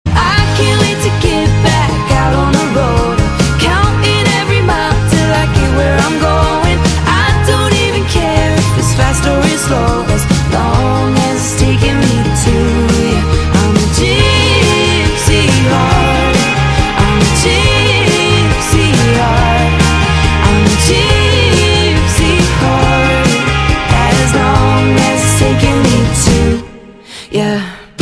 M4R铃声, MP3铃声, 欧美歌曲 53 首发日期：2018-05-14 09:45 星期一